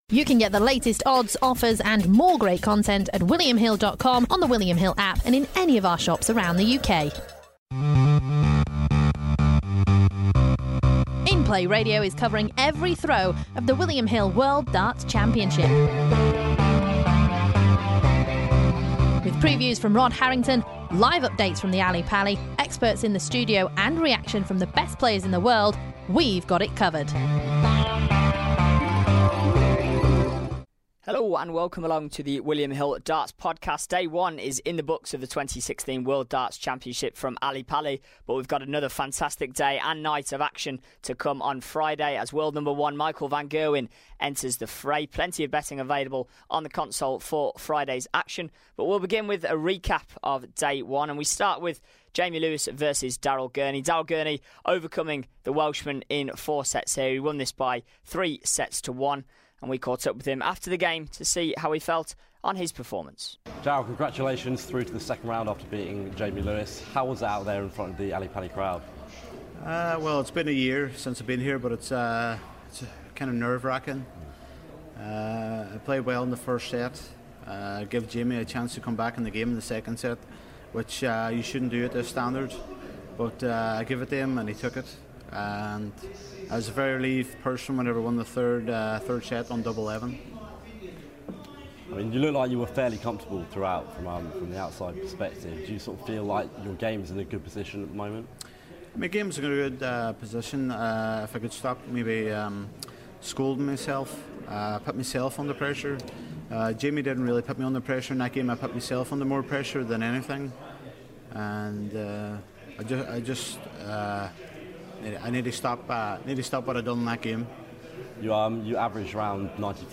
we also recap on yesterday's action with interviews from Daryl Gurney, Peter Wright and Gary Anderson.